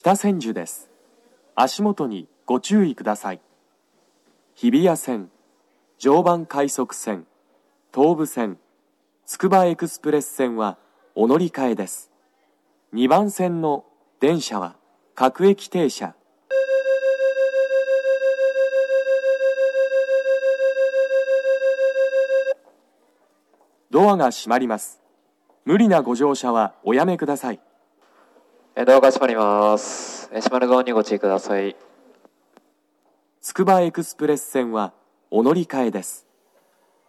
北千住駅　Kita-Senju Station ◆スピーカー：BOSE天井型
2番線発車ブザー